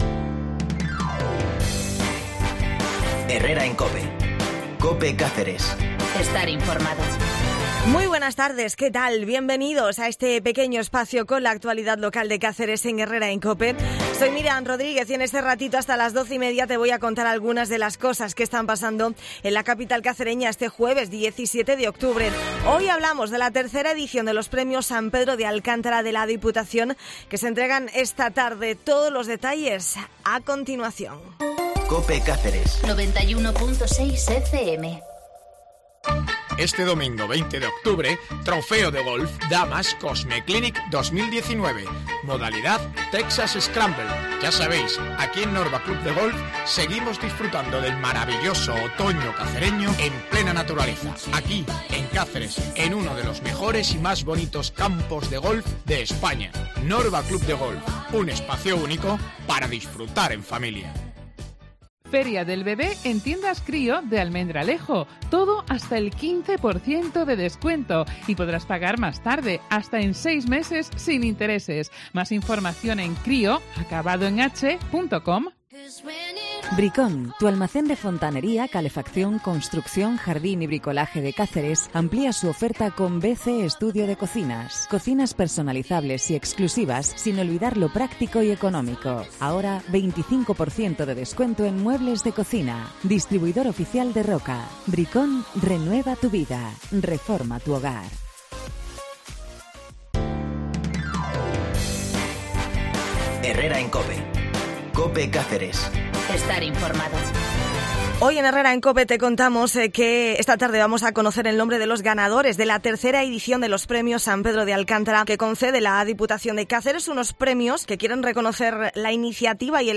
El diputado de Reto Demográfico, Álvaro Sánchez Cotrina, ha pasado hoy por los micrófonos de Herrera en Cope.